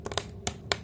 Fire Cracks.ogg